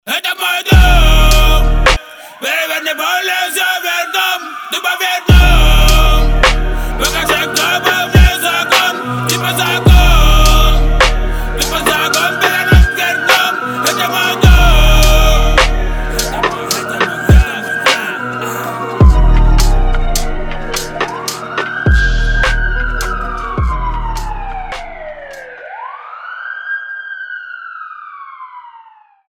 • Качество: 320, Stereo
Сирена
дворовые